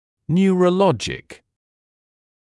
[ˌnjuərə’lɔʤɪk][ˌнйуэрэ’лоджик]неврологический